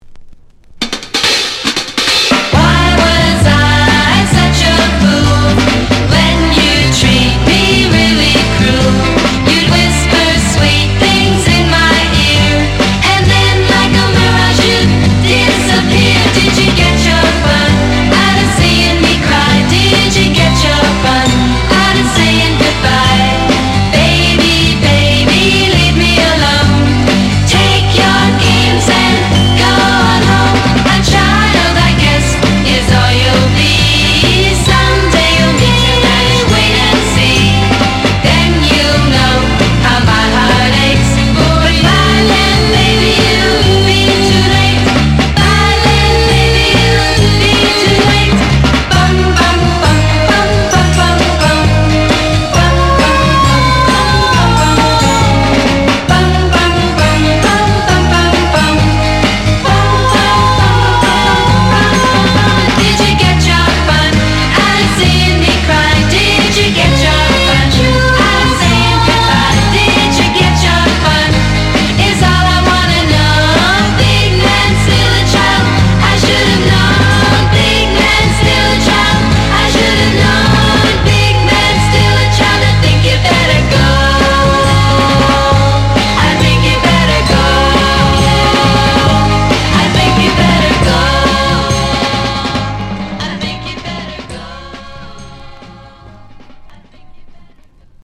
ヤング＆ビート・ガール・ポップ！
• 特記事項: MONO / DJ